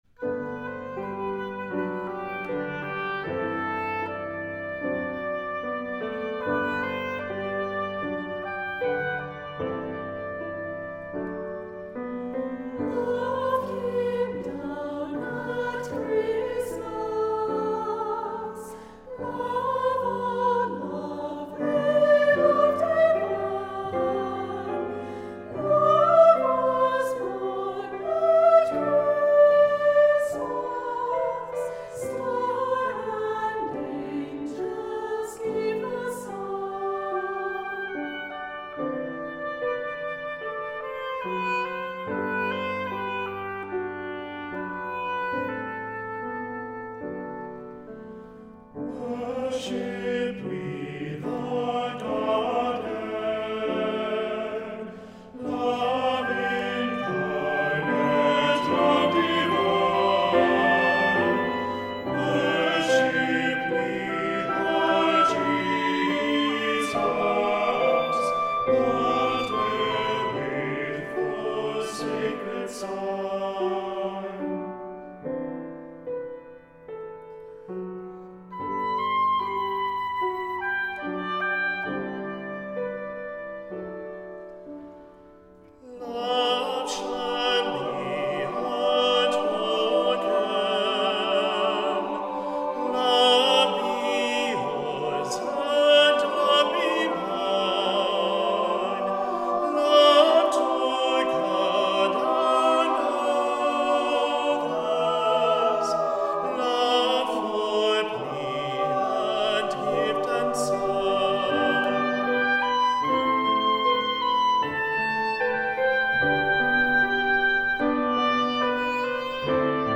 Accompaniment:      Piano, Tenor Solo
Music Category:      Choral
flowing Christmas setting